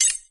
count_keys_01.ogg